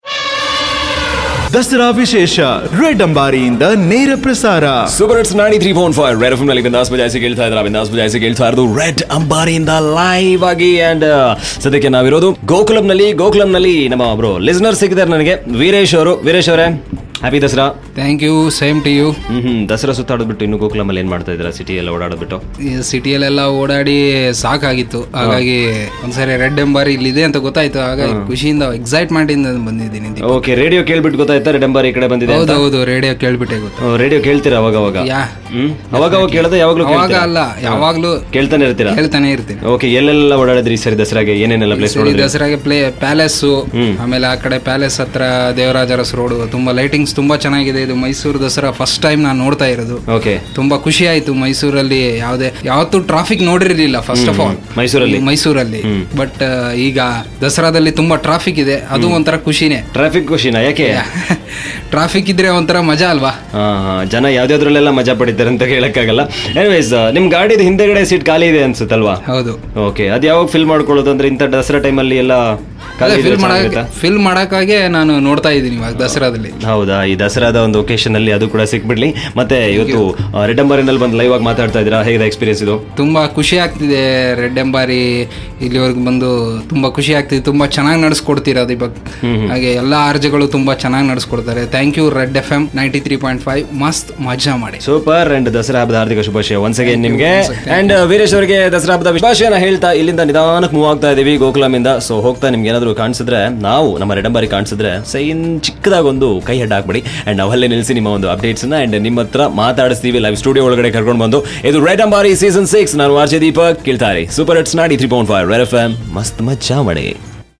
Red Ambari is a Studio on wheel where listeners and celebrities share their festive excitement